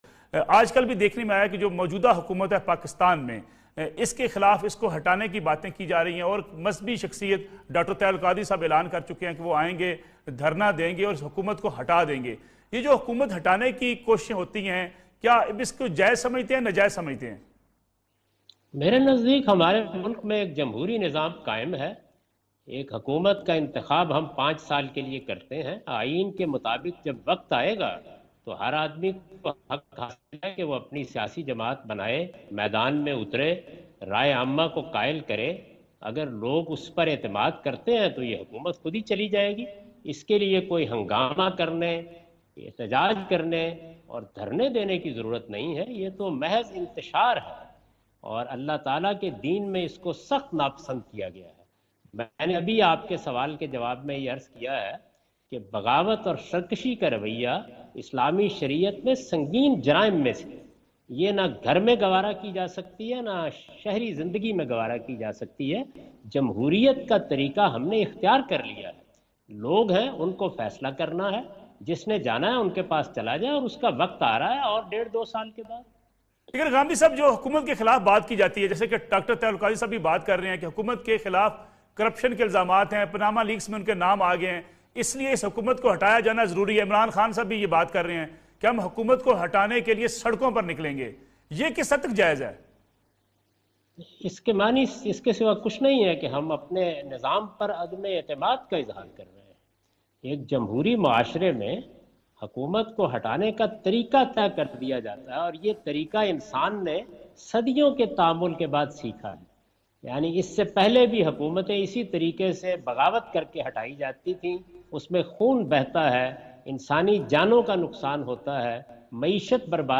Category: TV Programs / Neo News /
In this program Javed Ahmad Ghamidi answer the question about "Destabling an Elected Government" on Neo News.